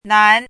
chinese-voice - 汉字语音库
nan2.mp3